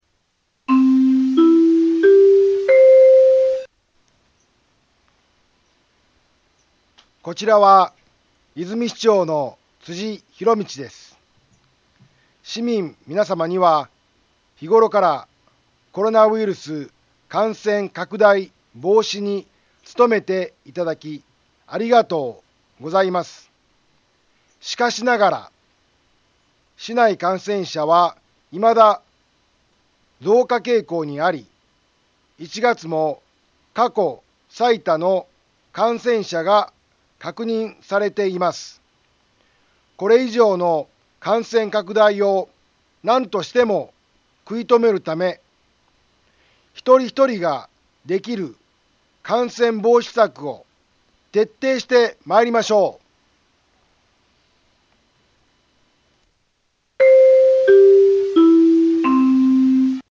Back Home 災害情報 音声放送 再生 災害情報 カテゴリ：通常放送 住所：大阪府和泉市府中町２丁目７−５ インフォメーション：こちらは、和泉市長の辻ひろみちです。